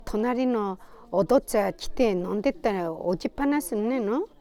Aizu Dialect Database
Final intonation: Rising
Location: Aizumisatomachi/会津美里町
Sex: Female